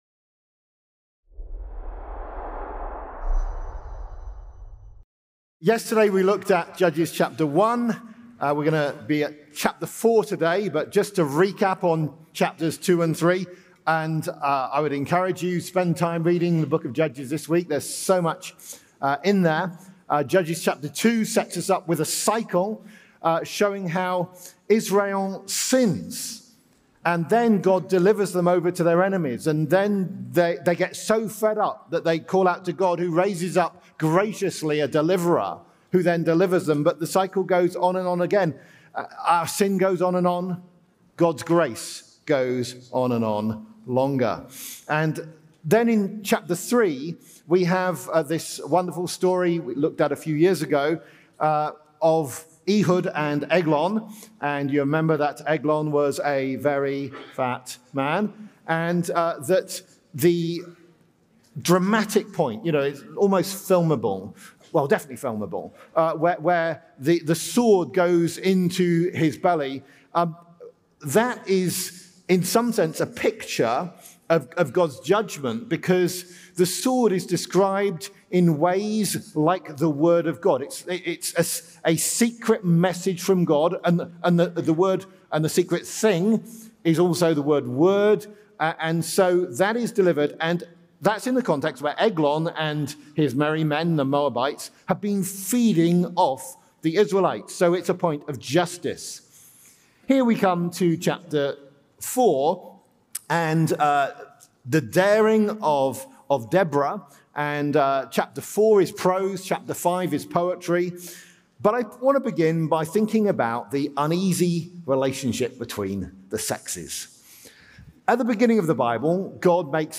Event: ELF Plenary Session